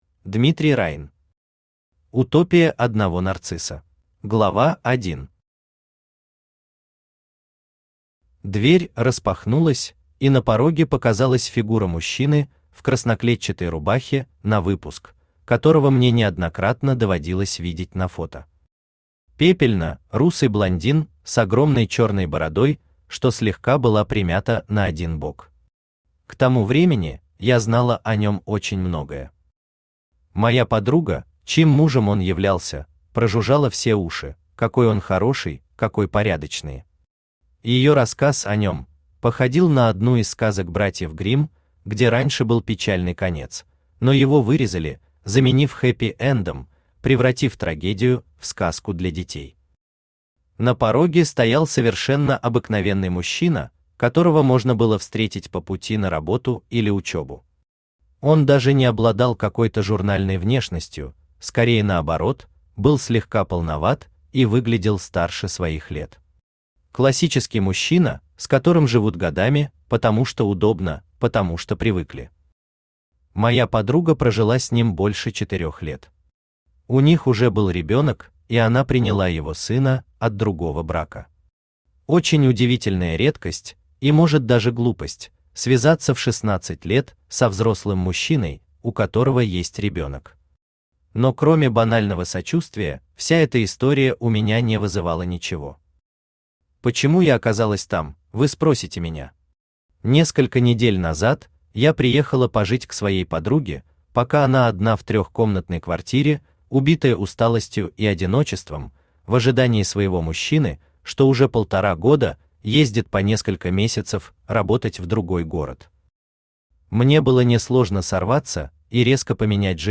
Aудиокнига Утопия одного Нарцисса Автор Дмитрий Райн Читает аудиокнигу Авточтец ЛитРес.